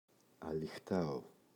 αλυχτάω [aliꞋxtao] – ΔΠΗ